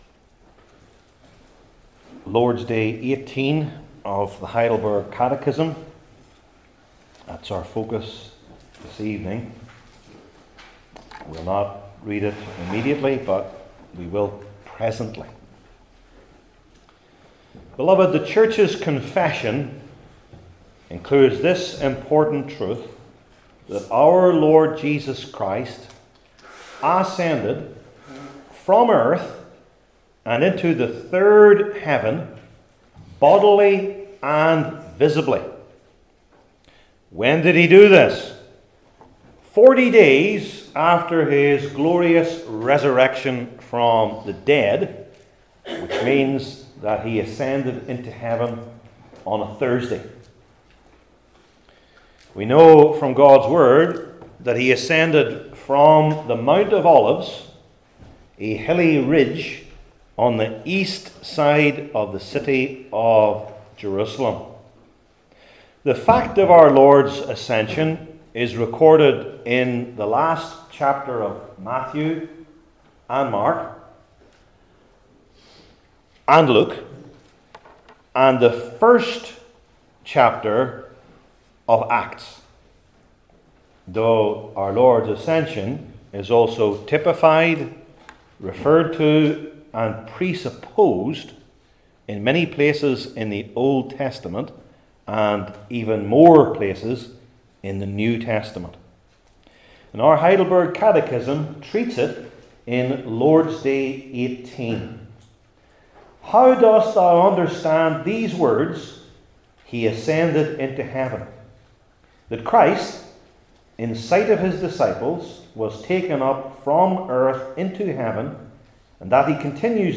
Heidelberg Catechism Sermons I. The Ascension and Christ II.